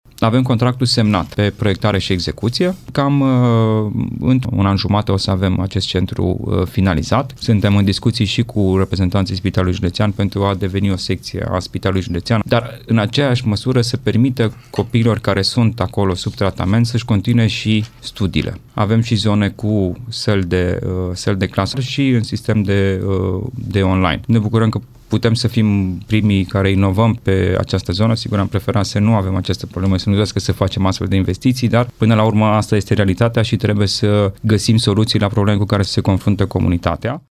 Vicepreședintele Consiliului Județean Timiș, Alexandru Iovescu, spune că centrul va fi primul de acest tip realizat de o administrație publică locală în România.